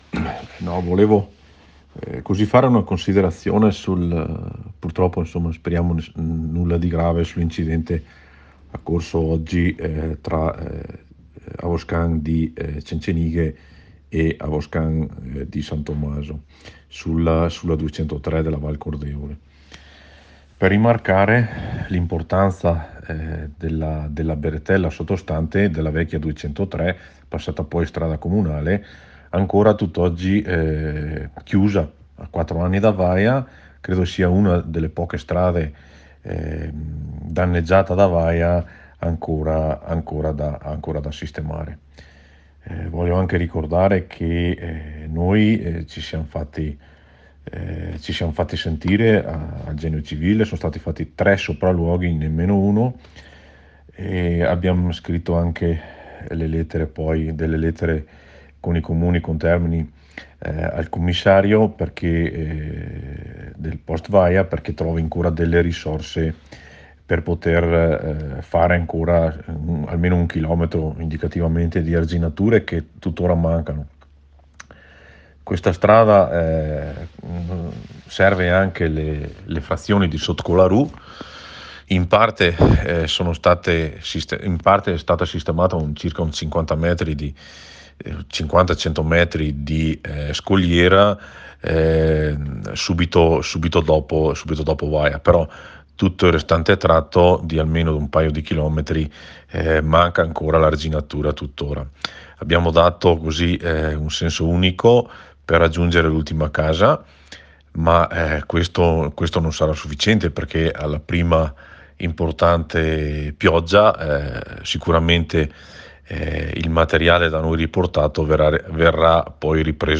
MORENO DE VAL, SINDACO DI SAN TOMASO